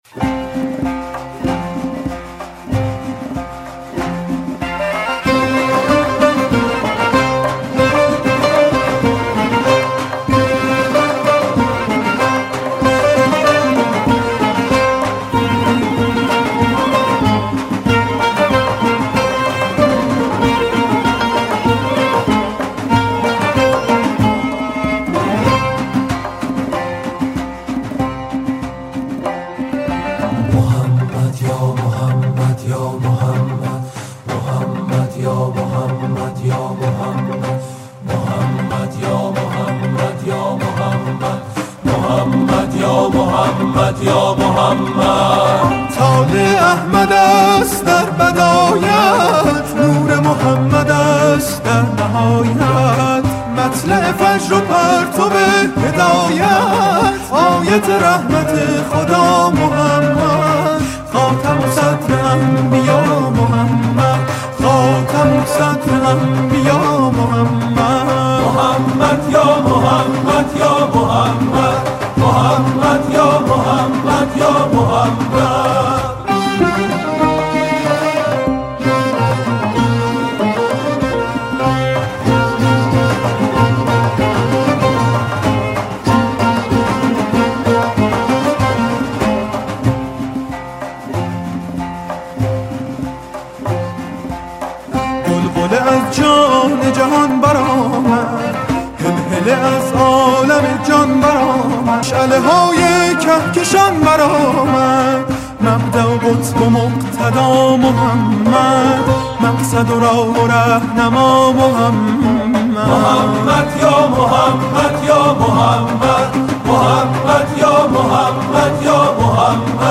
سرودهای ۱۷ ربیع الاول